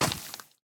Minecraft Version Minecraft Version snapshot Latest Release | Latest Snapshot snapshot / assets / minecraft / sounds / mob / husk / step2.ogg Compare With Compare With Latest Release | Latest Snapshot
step2.ogg